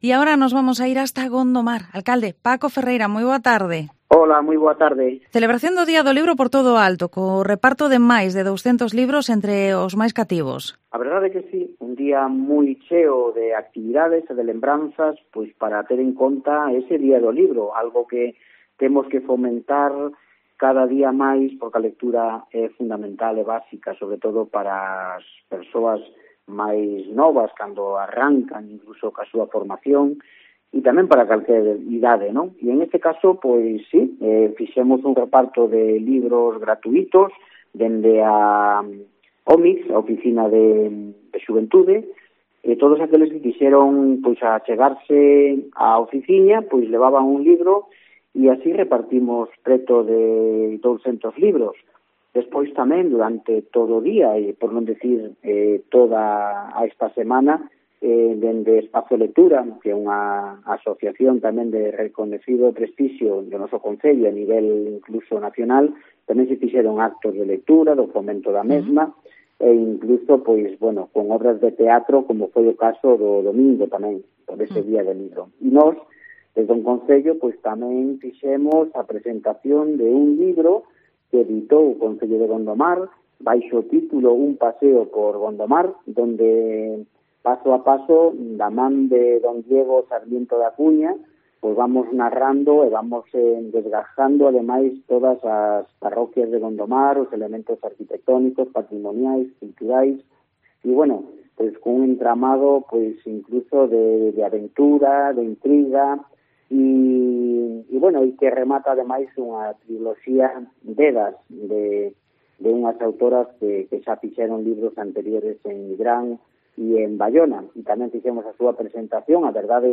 Entrevista Alcalde de Gondomar, Paco Ferreira